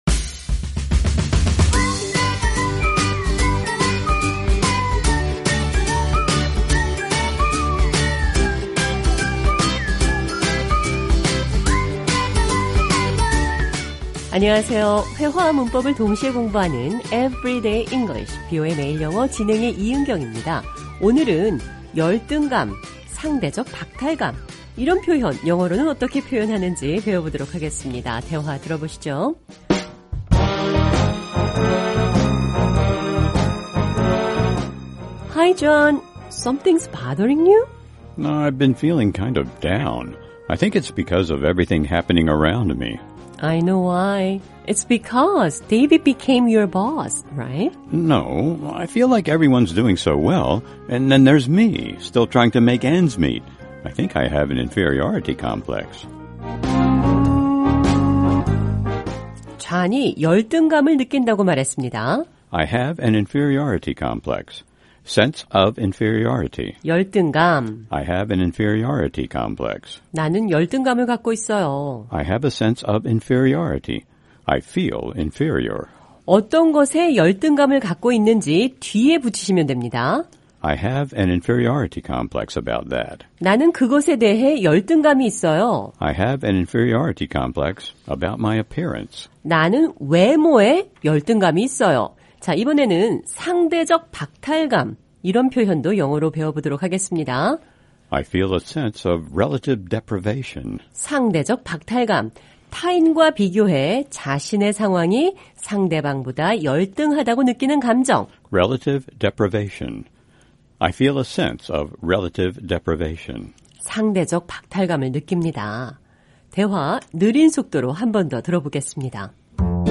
오늘은 열등감, 상대적 박탈감, 영어로는 어떻게 표현하는지 배워보겠습니다. 대화 들어보시죠.